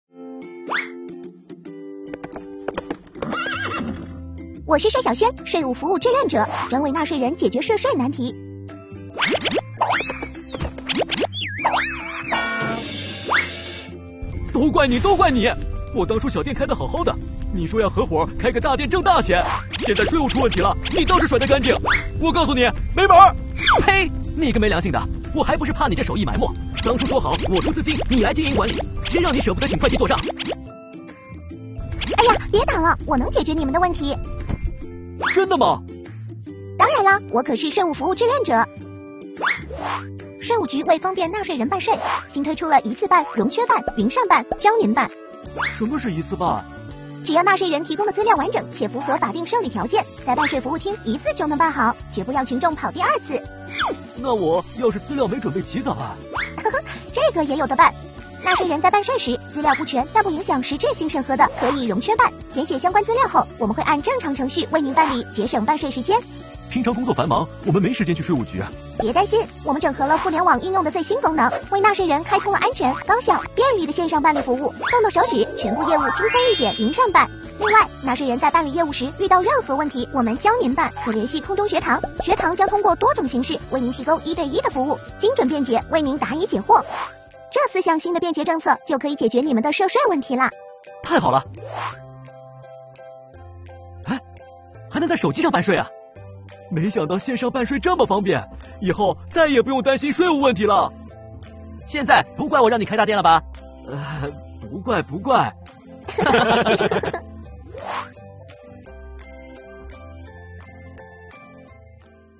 作品采用动画的形式，制作精良，故事情节真实有趣，音效轻松活泼，趣味性十足，能够引发受众观看兴趣。